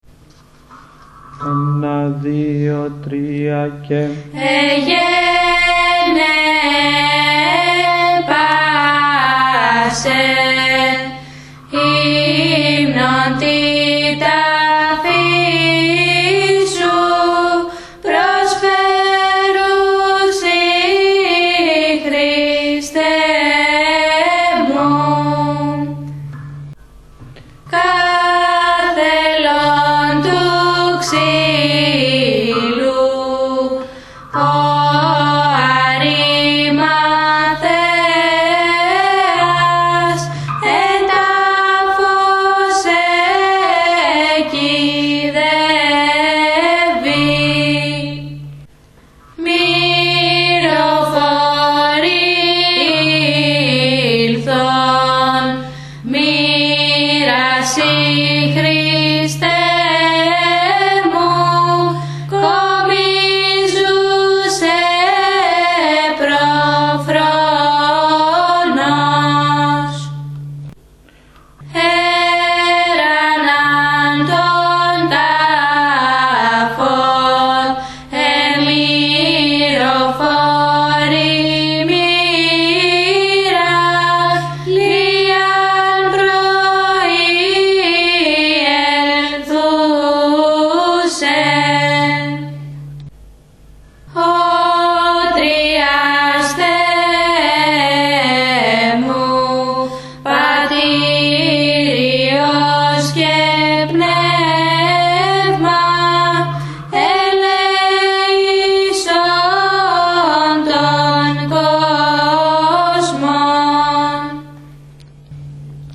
03-Εγκώμια-Γ΄-στάση.mp3